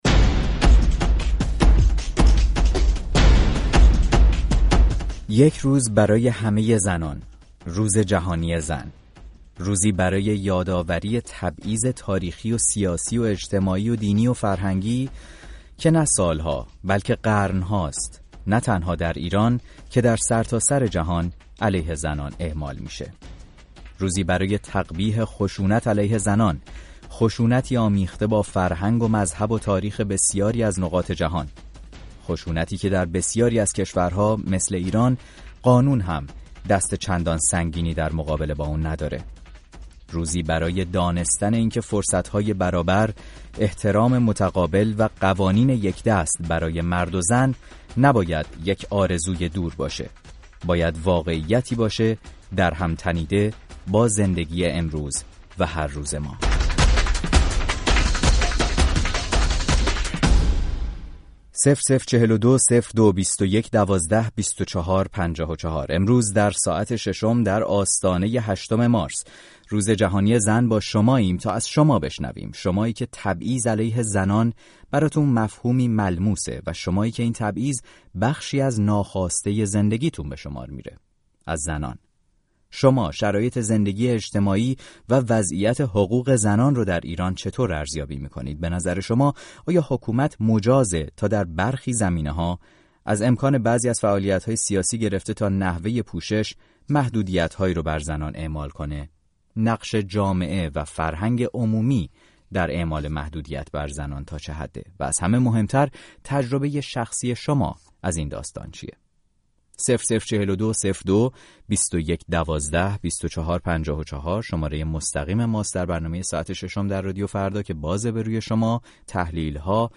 شرایط فعلی حقوق زنان در ایران چقدر با تساوی حقوق زن و مرد فاصله دارد؟ برنامه «ساعت ششم» در آستانه روز جهانی زن، میزبان مخاطبان رادیو فردا و مهرانگیز کار حقوقدان و پژوهشگر مسائل زنان بود.